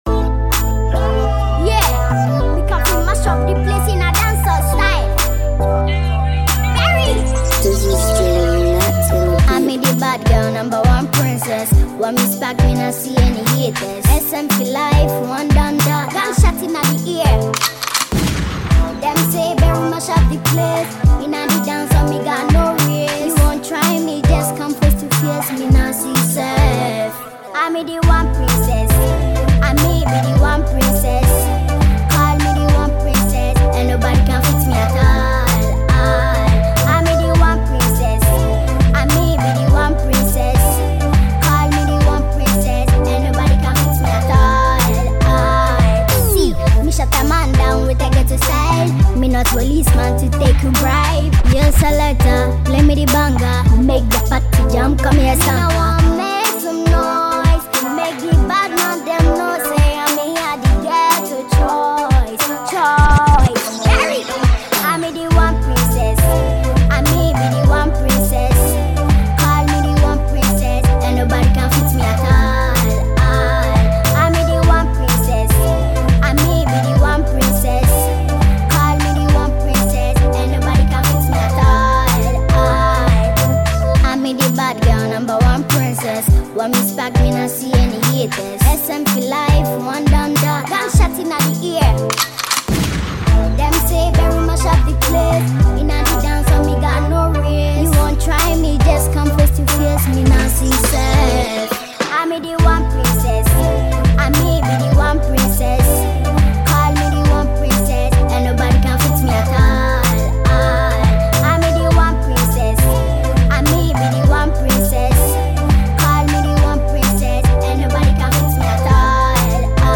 Ghana Music
Ghanaian young talented singer